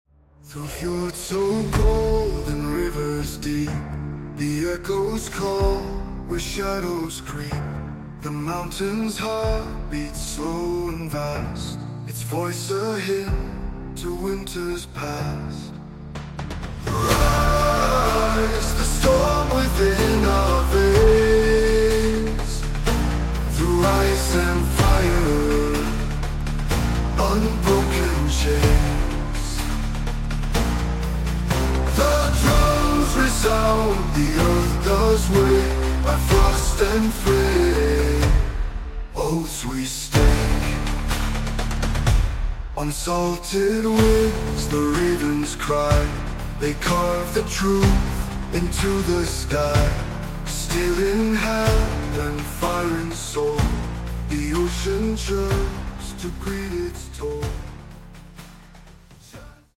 epic Viking music with driving energy and AI vocals